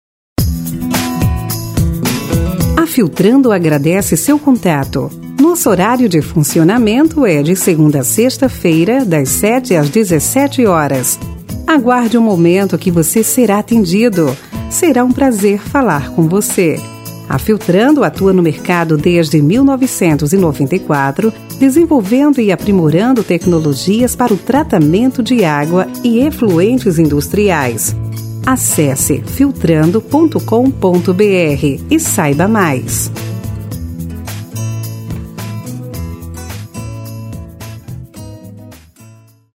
por gentileza, seguir com o mesmo audio de fundo da ura em anexo.